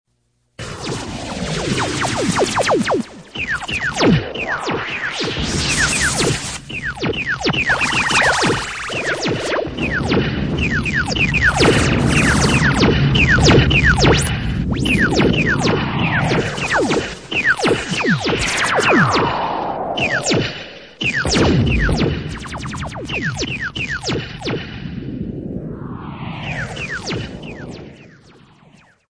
Здесь собраны аудиозаписи, которые раскрывают весь потенциал стереозвука — от успокаивающих мелодий до динамичных эффектов.
Бой в космической пустоте